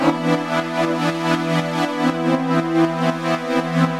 Index of /musicradar/sidechained-samples/120bpm
GnS_Pad-alesis1:8_120-E.wav